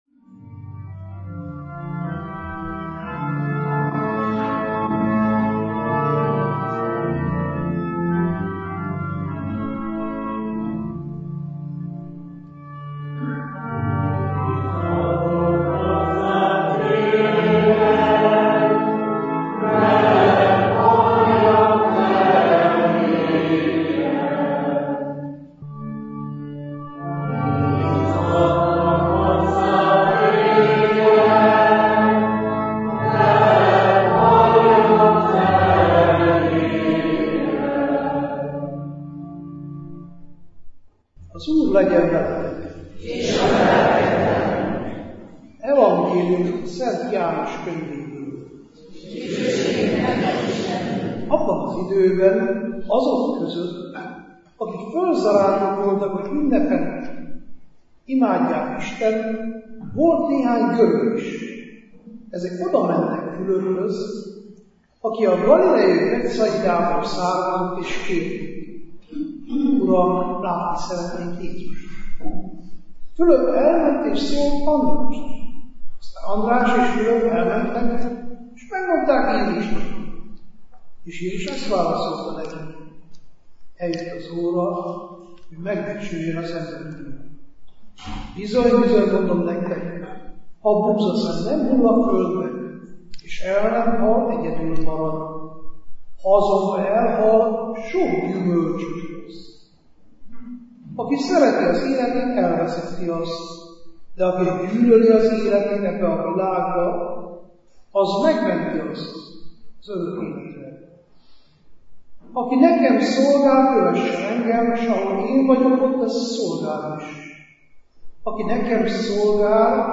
Böjte Csaba clevelandi szentmiséje – Bocskai Rádió
Böjte Csaba, erdélyi ferences rendi atya, 2015. március 22-én, azaz múlt vasárnap Clevelandbe látogatott és délelőtt 11 órakor ünnepi szentmisét végzett a Szent Imre Római Katolikus templomban.